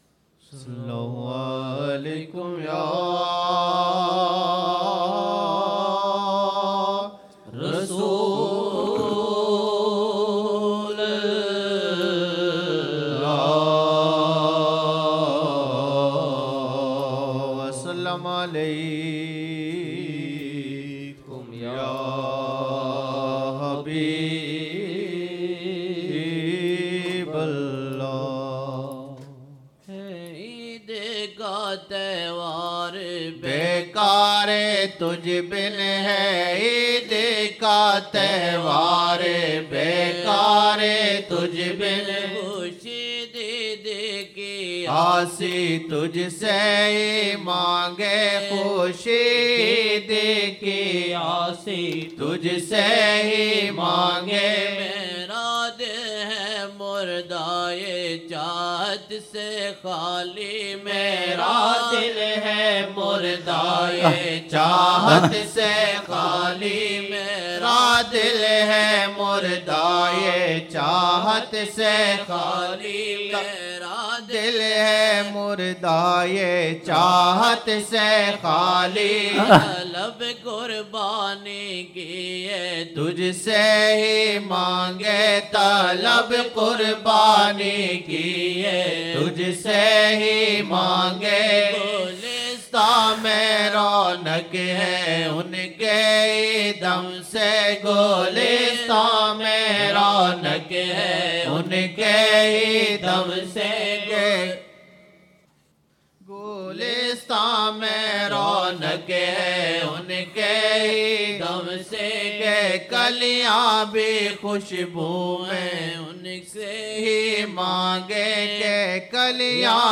Naat( hay aeed ka tahawar be ka tajh ban khushi aeed ki aasi tajh hi se mangin) 2007-01-01 01 Jan 2007 Old Naat Shareef